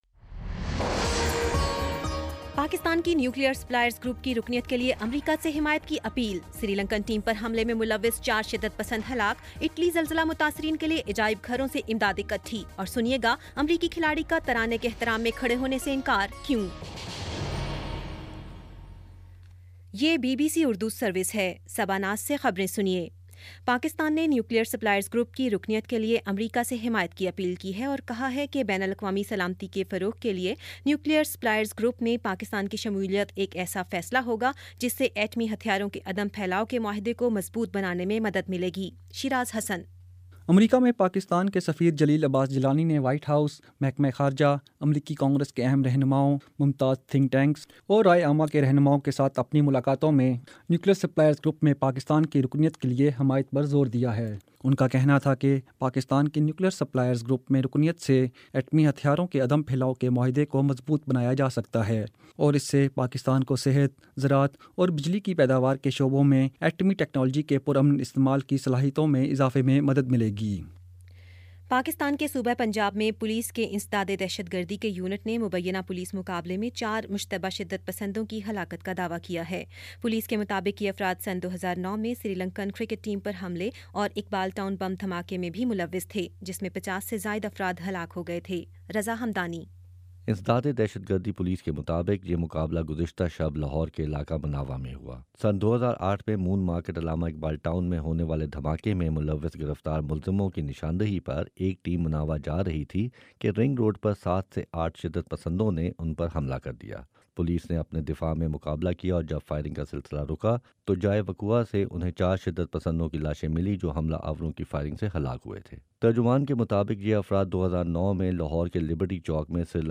اگست 28 : شام چھ بجے کا نیوز بُلیٹن